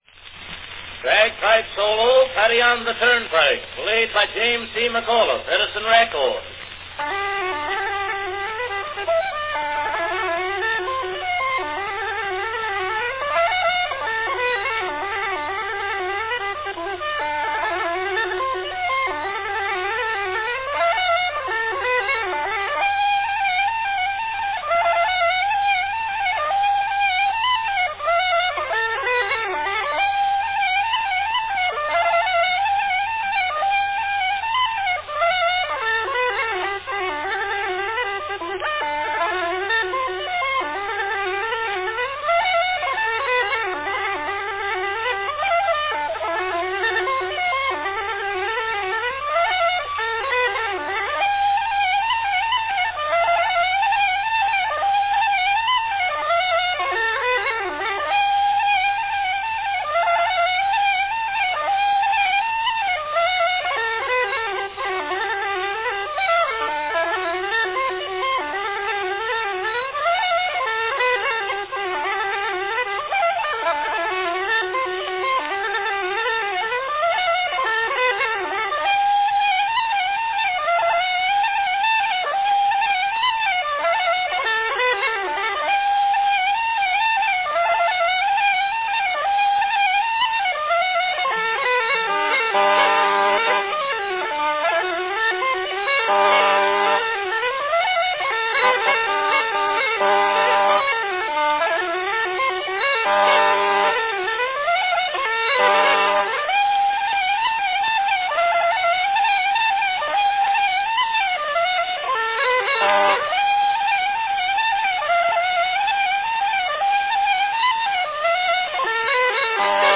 enjoy an early bagpipe recording of Paddy on the Turnpike
Company Edison's National Phonograph Company
Category Bagpipe solo
He was a specialist of the Irish uilleann bagpipe, which tended to record better than the Scottish bagpipe.